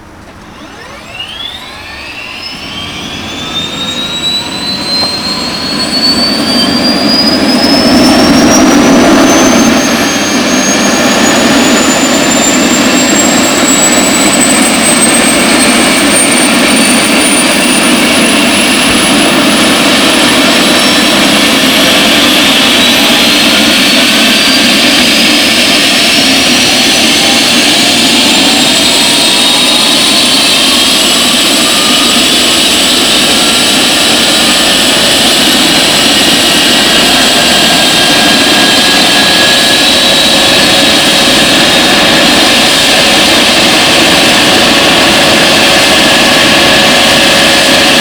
strt_ec135_out2.wav